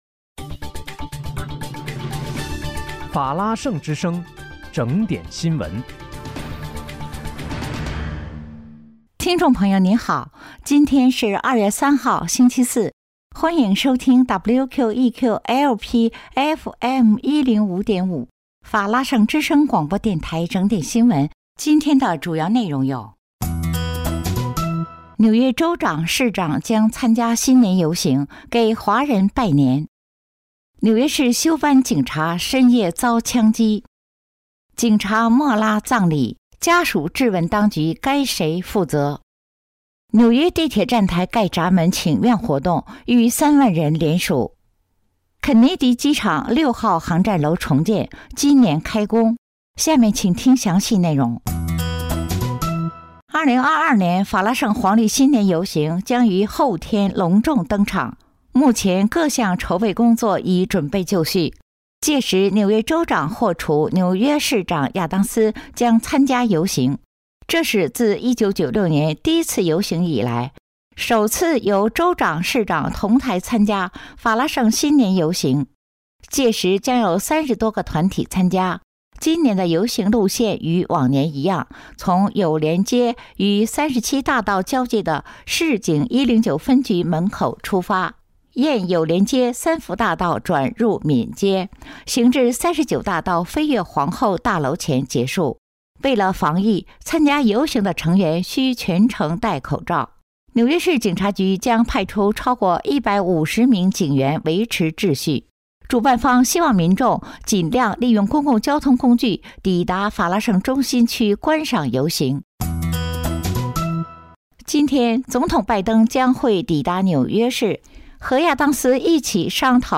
2月3日（星期四）纽约整点新闻